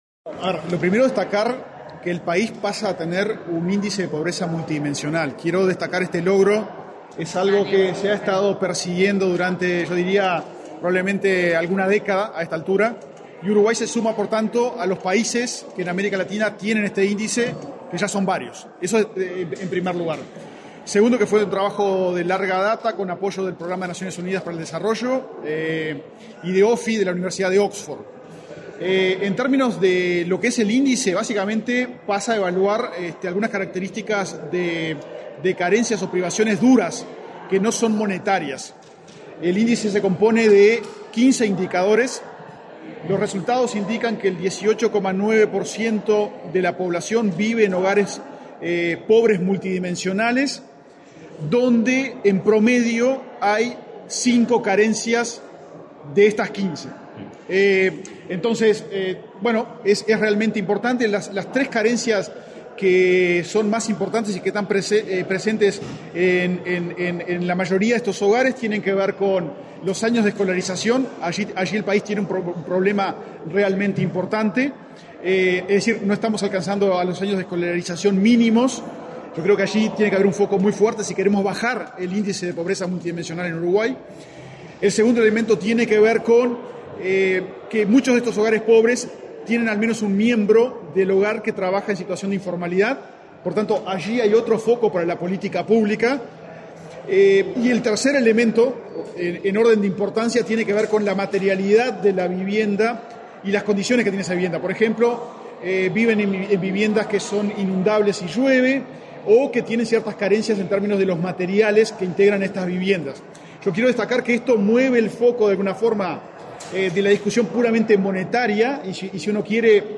Declaraciones del director del INE, Diego Aboal
Declaraciones del director del INE, Diego Aboal 19/02/2025 Compartir Facebook X Copiar enlace WhatsApp LinkedIn Este miércoles 19 en la Torre Ejecutiva, el director del Instituto Nacional de Estadística (INE), Diego Aboal, dialogó con la prensa, luego de encabezar el acto de presentación del informe del índice de pobreza multidimensional de 2024.